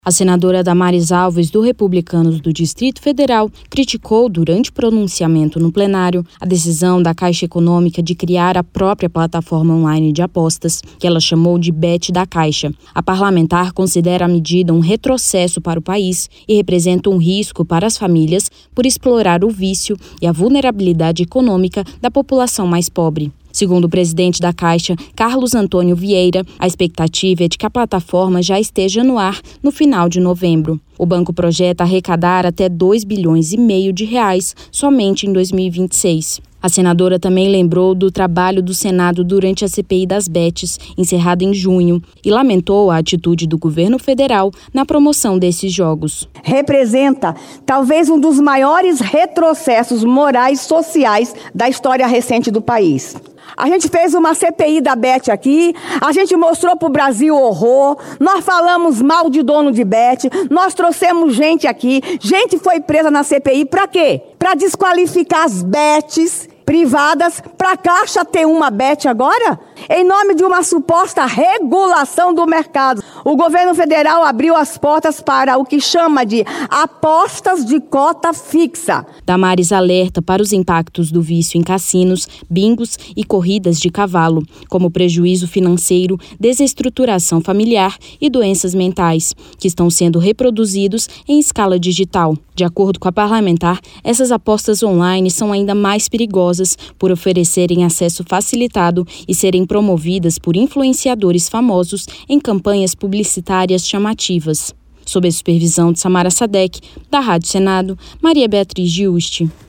Pronunciamento
A senadora Damares Alves (Republicanos-DF) criticou, durante pronunciamento no Plenário, a decisão da Caixa Econômica de criar a própria plataforma online de apostas, que ela chamou de Bet da Caixa.